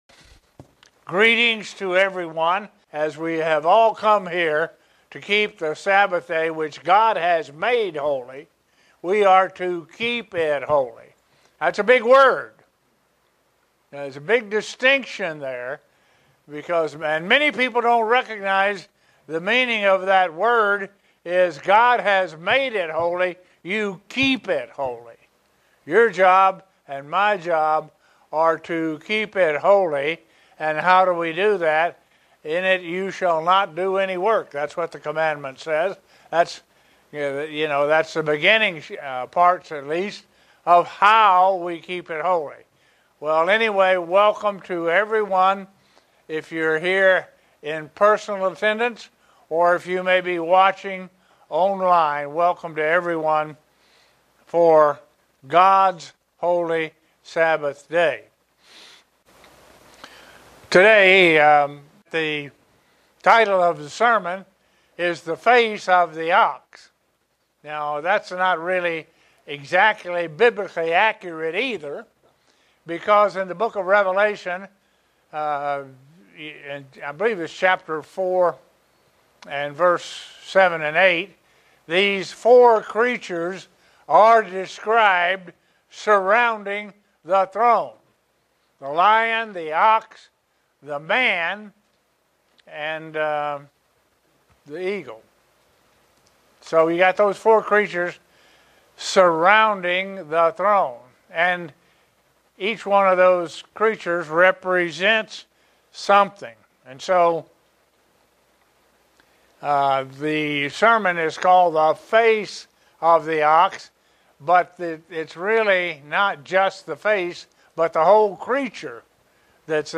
Given in Buffalo, NY
Print How the Gospel of Mark uniquely presents Christ as the great Ox. sermon Studying the bible?